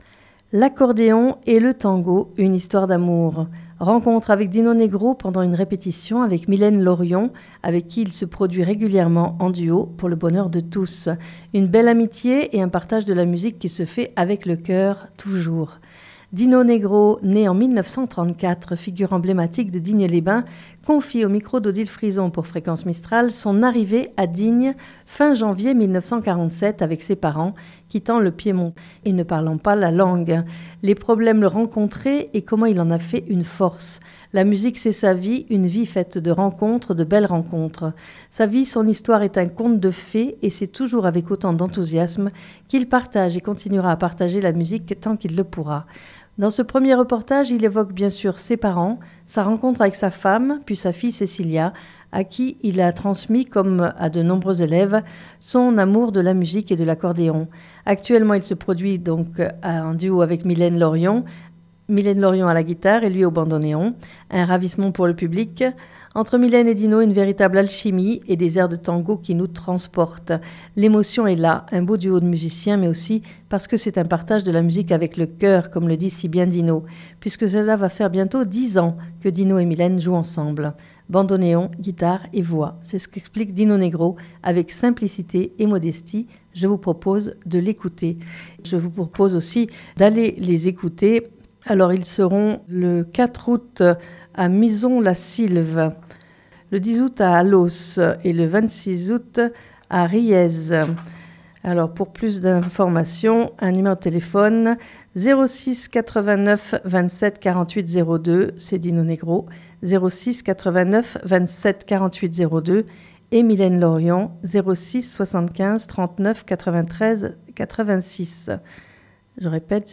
Dans ce 1er reportage